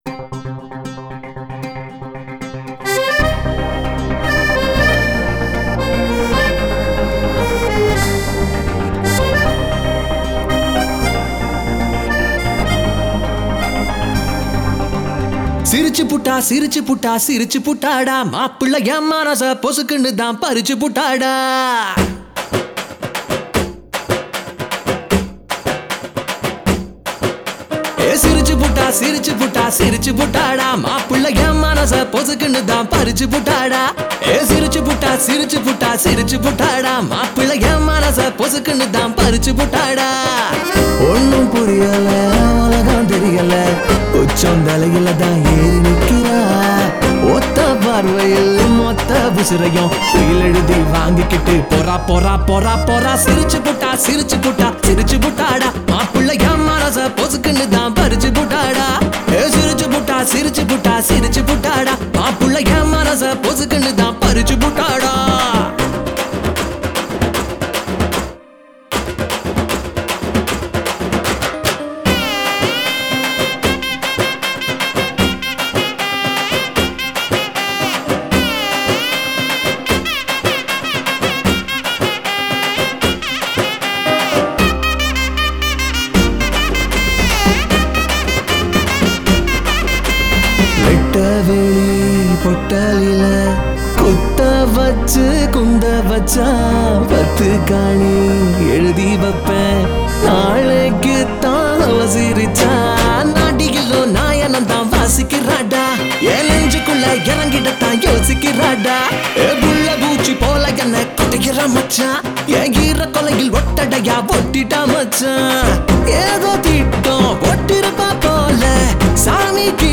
Category: DJ AND BAND (BEND) ADIVASI REMIX